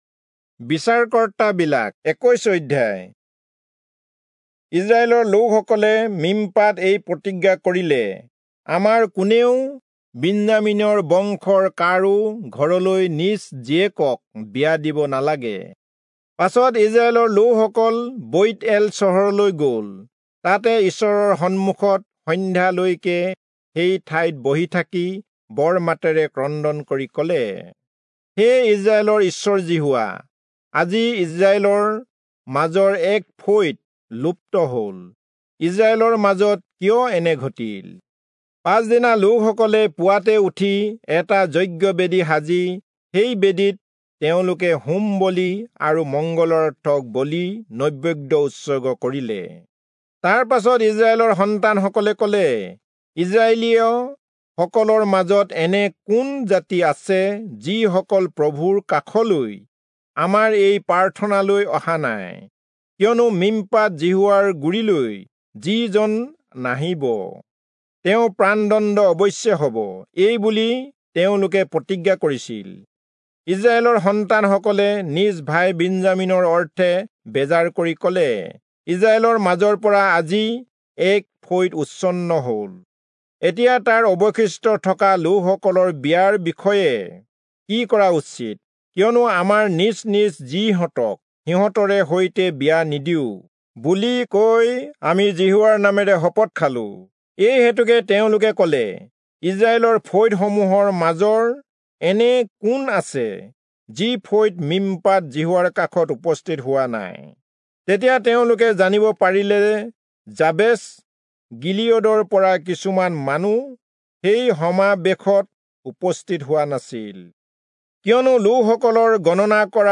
Assamese Audio Bible - Judges 21 in Mrv bible version